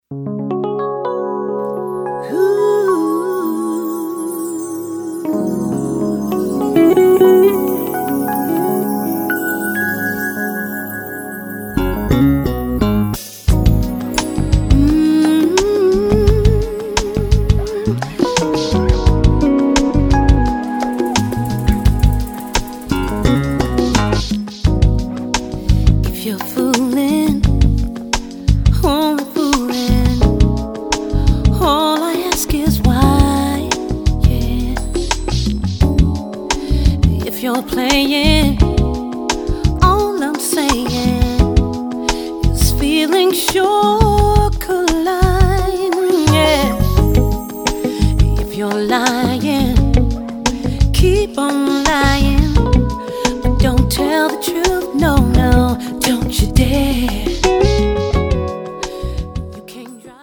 (20 Latin 3-Step, Rumba & Slow Dance Favorites)<